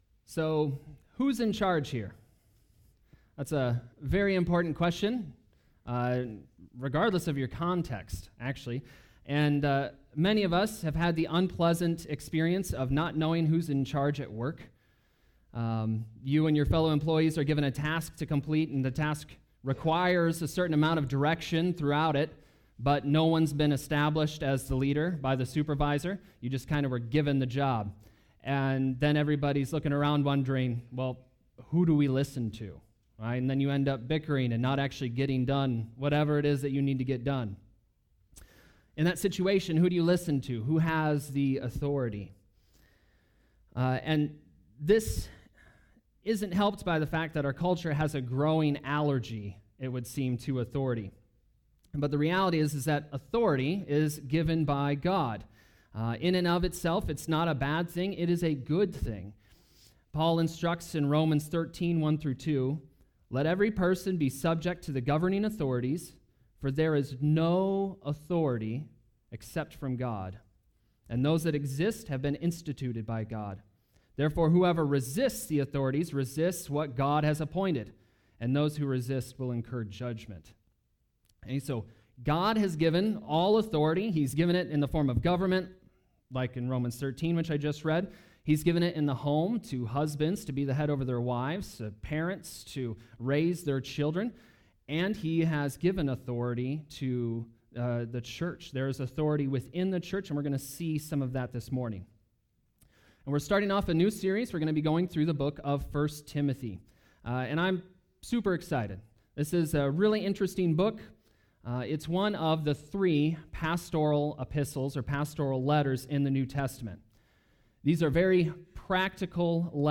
Sermon Preached Sunday